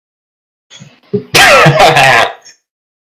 (D)(Pause)Laugh pause
Category: Comedians   Right: Personal